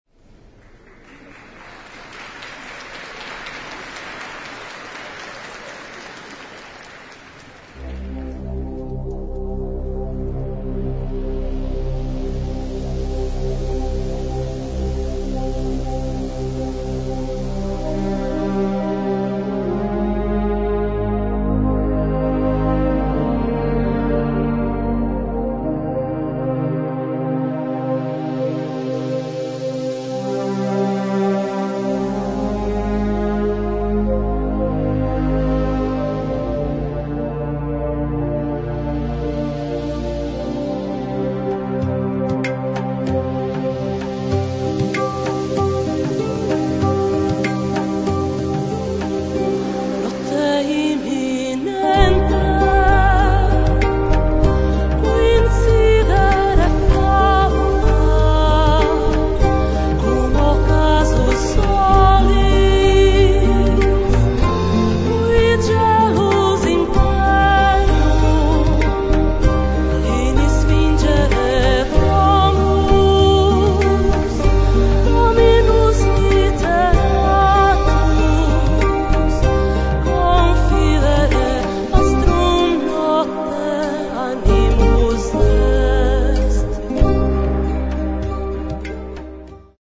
Em Concerto